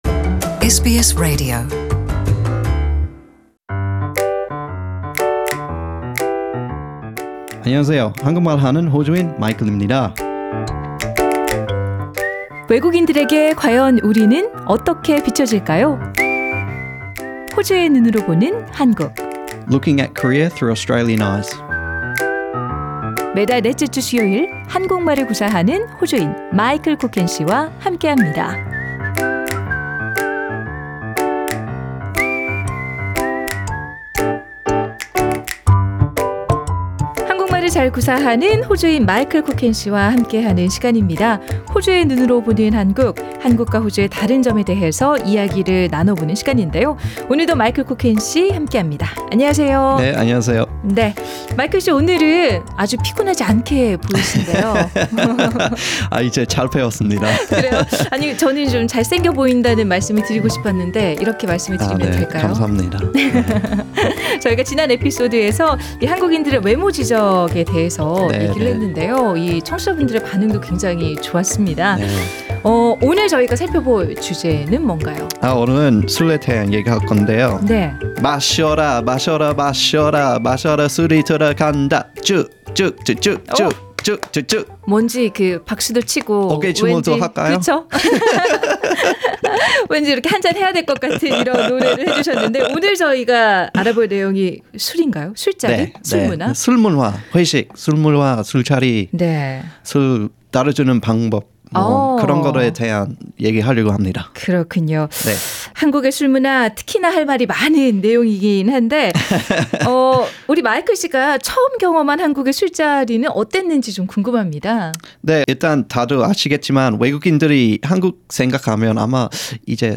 한국말을 구사하는 호주인